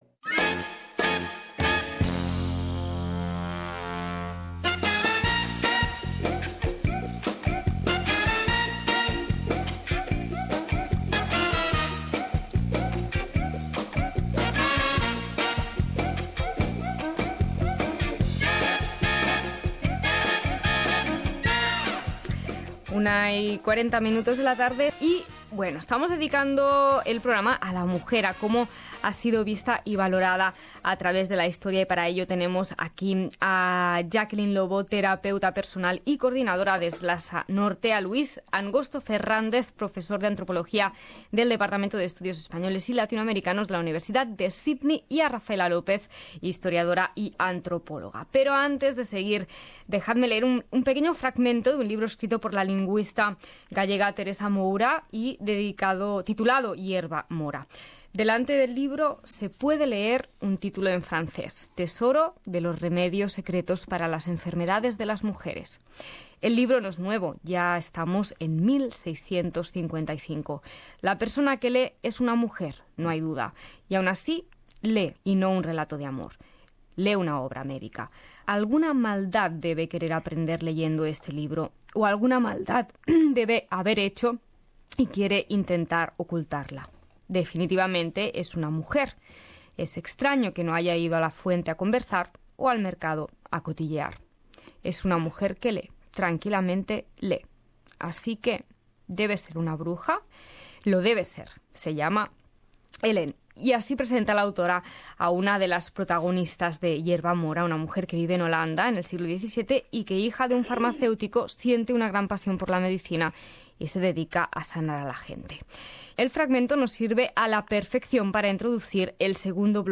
En Vitamina L tres expertos respondieron a estas preguntas.